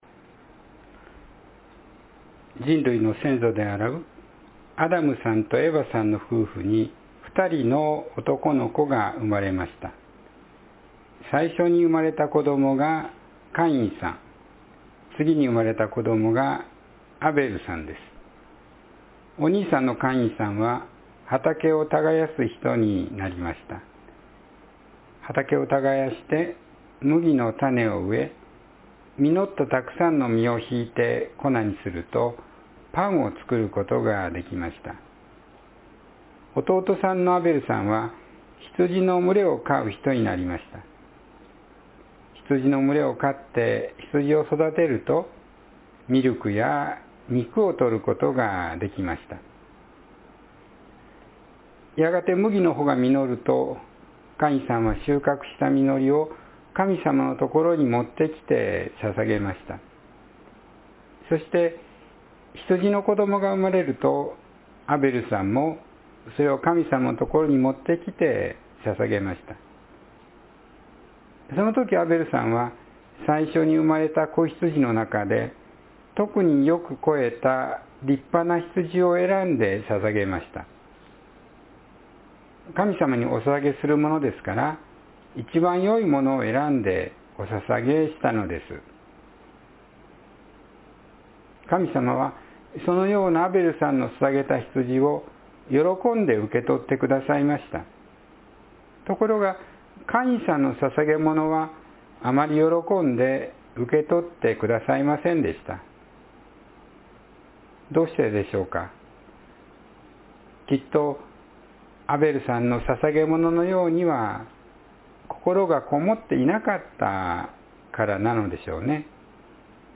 兄弟を殺したカイン（2026年2月1日・子ども説教）